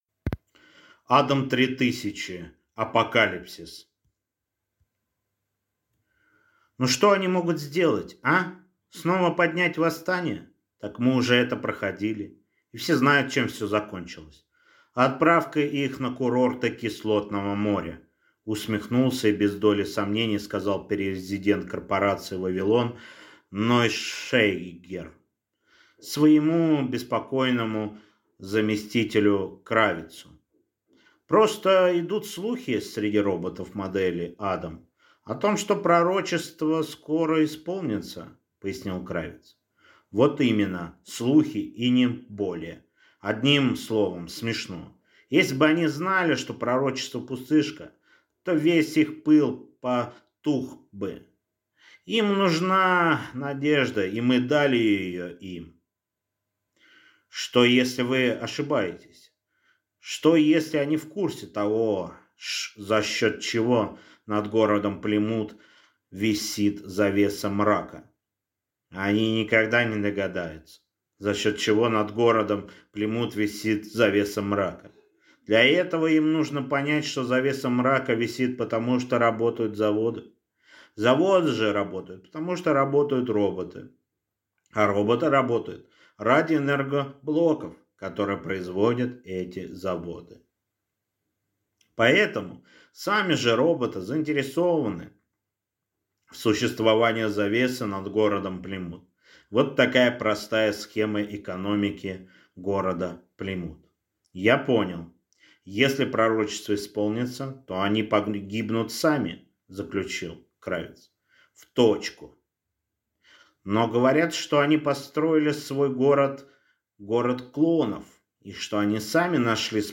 Аудиокнига Адам-3000. Апокалипсис | Библиотека аудиокниг